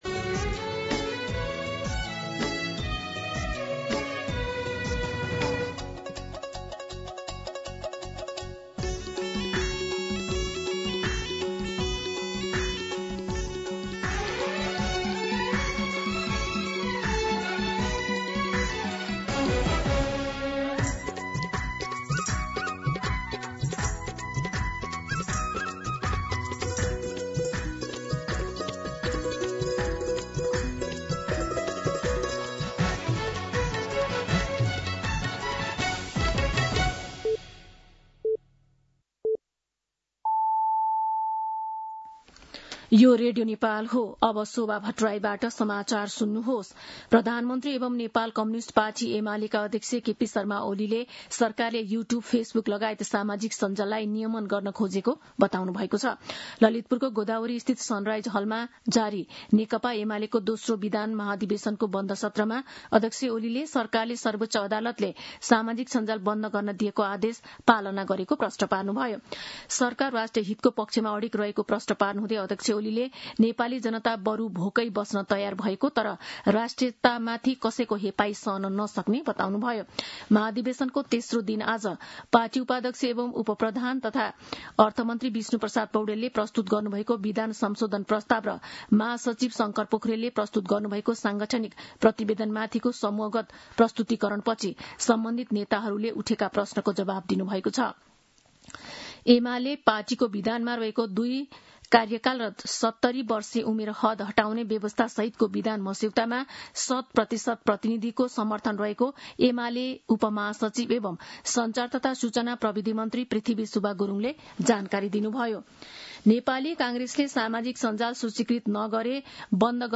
दिउँसो ४ बजेको नेपाली समाचार : २२ भदौ , २०८२